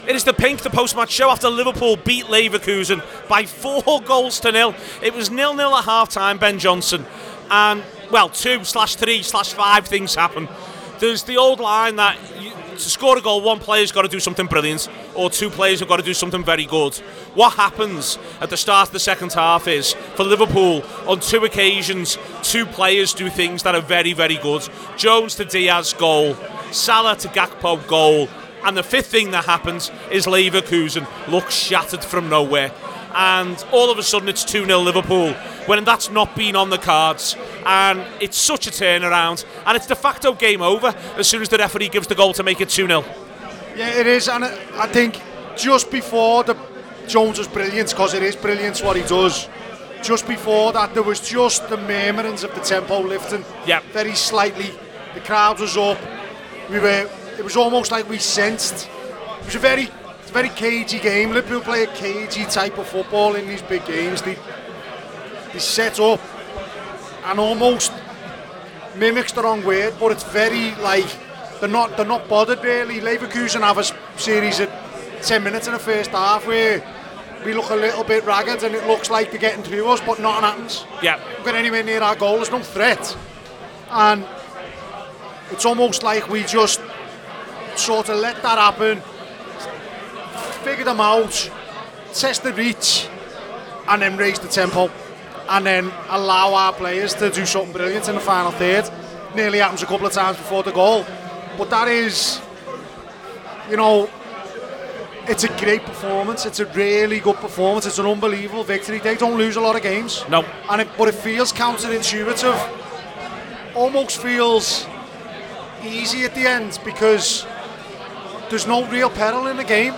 The Anfield Wrap’s post-match reaction podcast after Liverpool 4 Leverkusen 0 in the Champions League at Anfield.